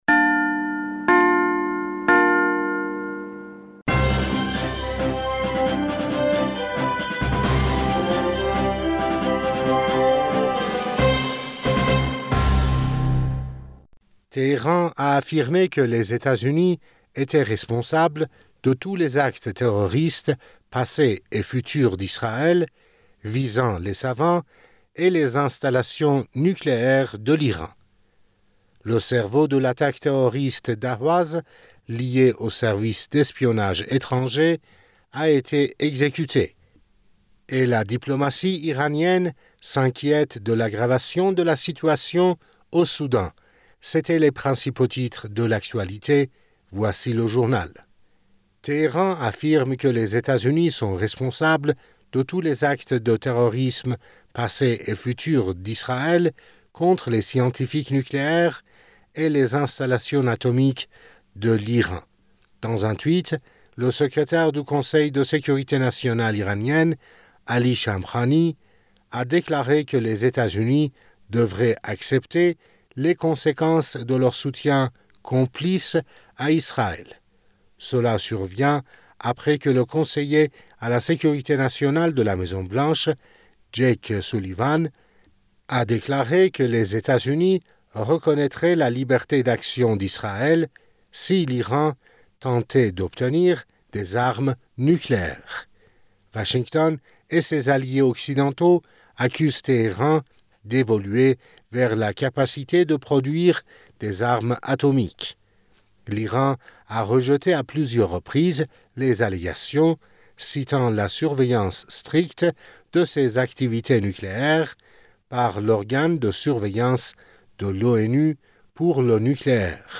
Bulletin d'information du 06 Mai 2023